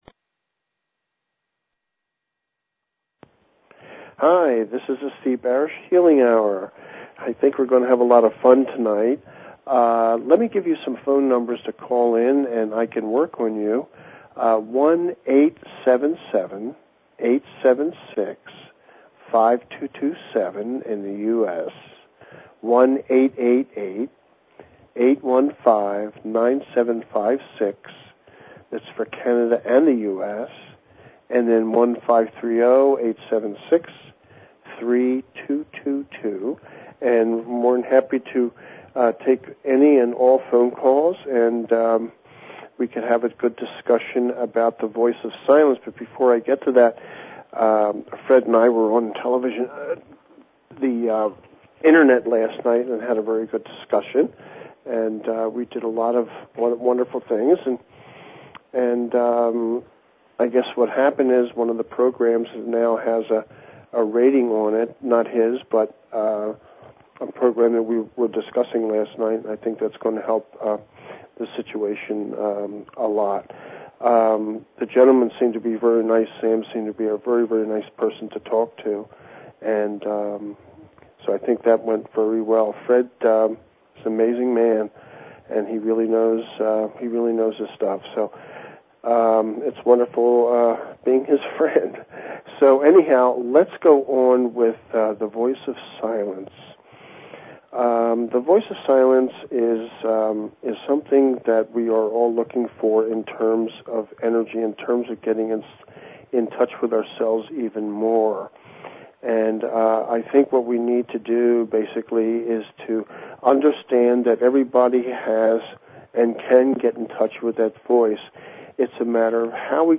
"The Voice Of Silence"--understanding our inner voice and how to work with it. You are always invited to call in, participate and be healed.